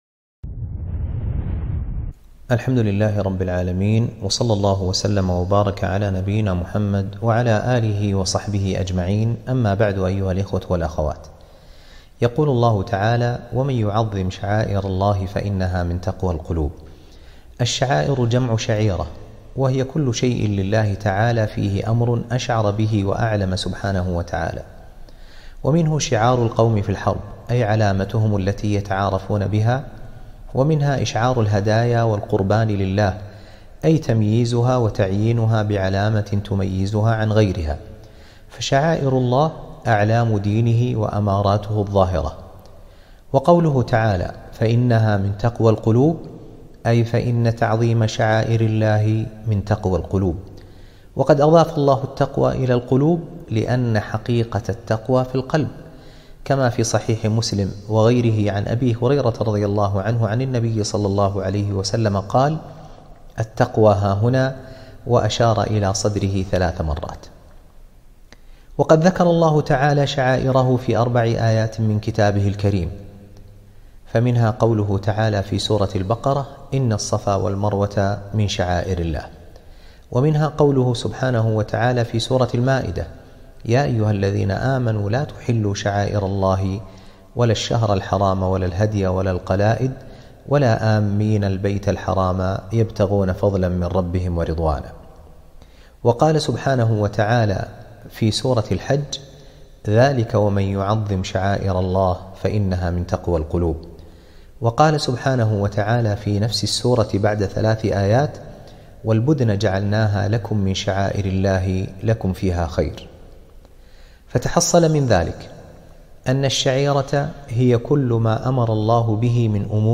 كلمة - تعظيم شعائر الله